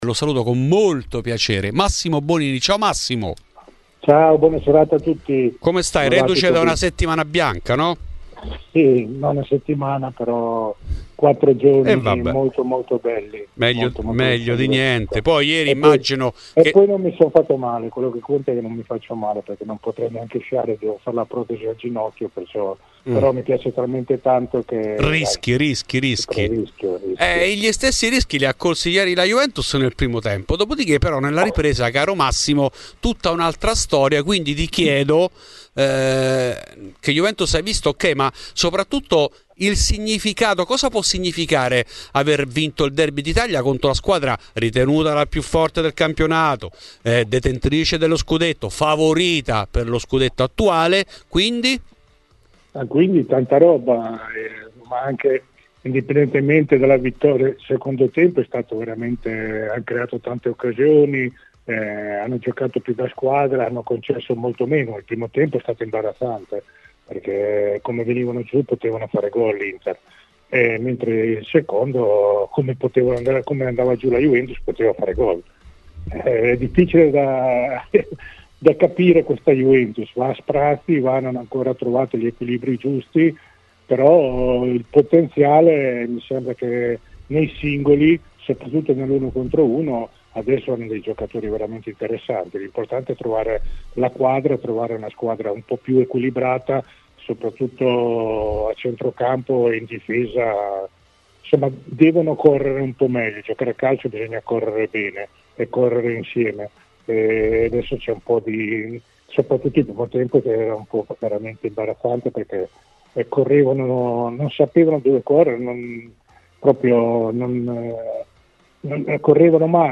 Nel frattempo crescono i rimpianti per i tanti punti persi per strada che hanno estromesso la Vecchia Signora dalla corsa scudetto. In ESCLUSIVA a Fuori di Juve l'ex Massimo Bonini che è tornato sul caso Danilo ed ha detto la sua anche sulle possibilità scudetto della Vecchia Signora.